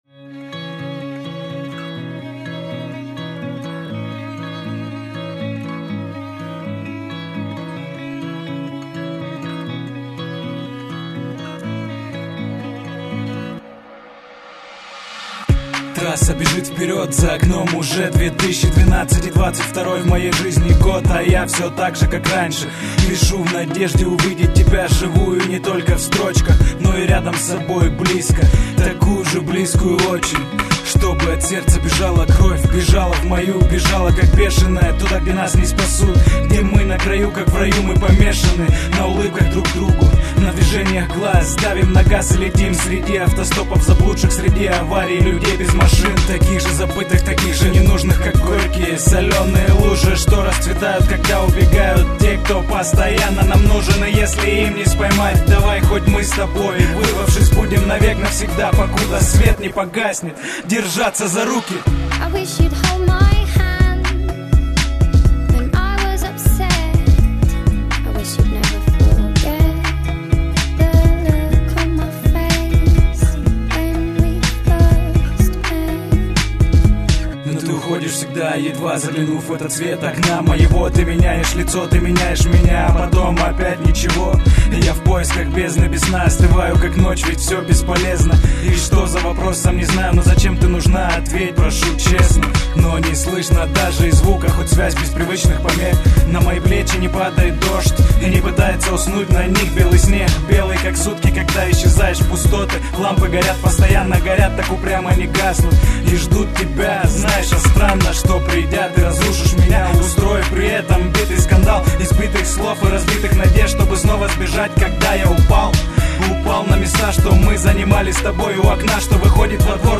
Хорошо!!!.. женский вокал в тему…